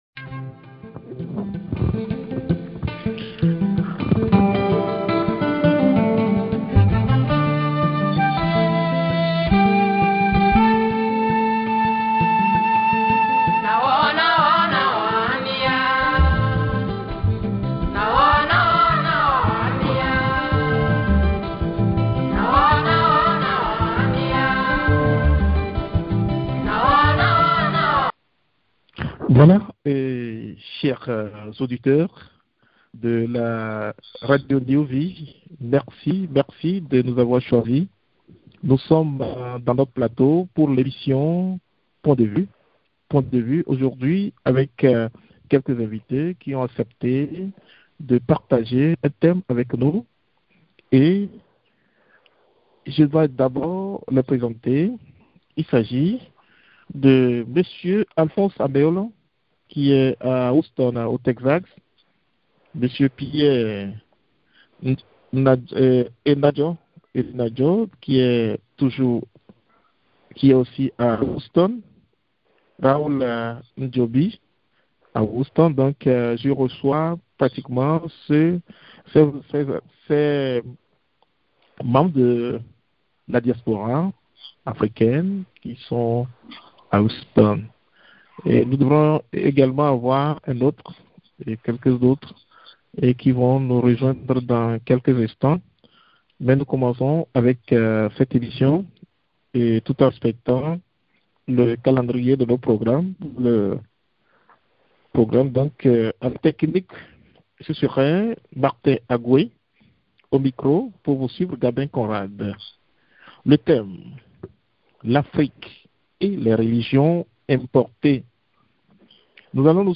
Les invites à cette emission nous aident à faire les analyses et tirer les approches de solutions pour remedier à ce drames.